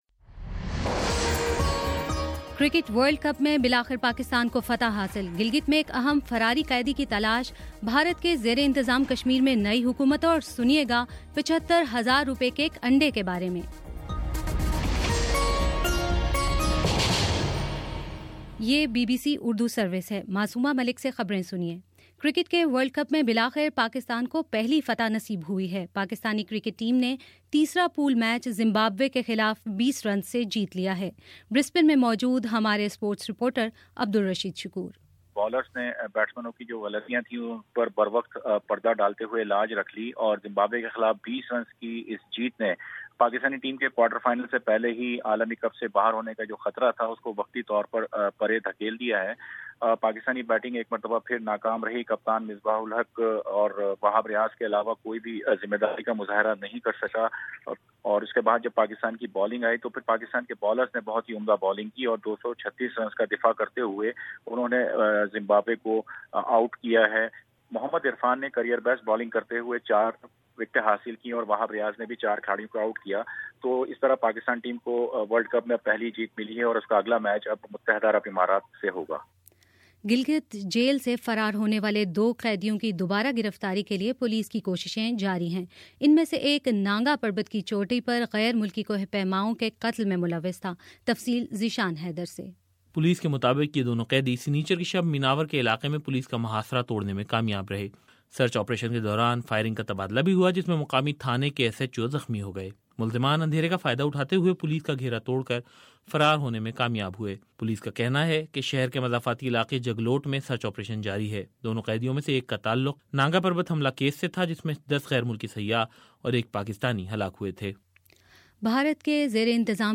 مارچ 01: شام پا نچ بجے کا نیوز بُلیٹن